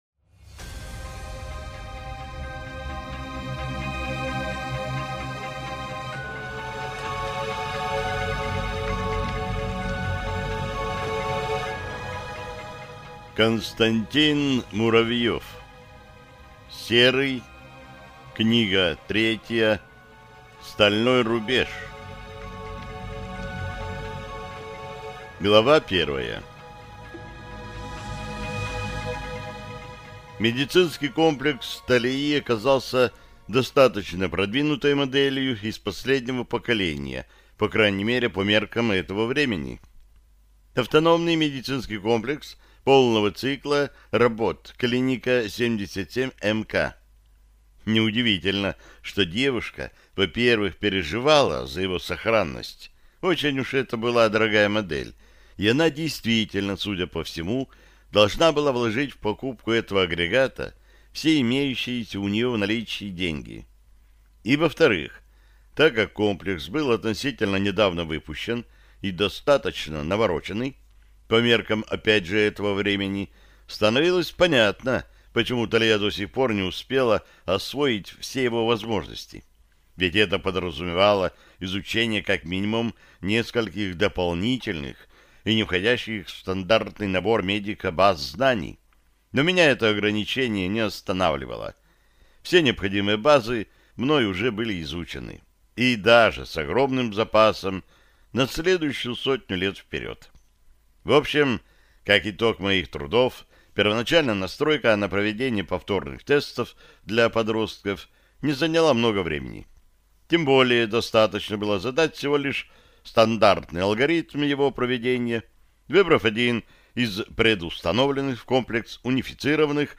Аудиокнига Стальной рубеж | Библиотека аудиокниг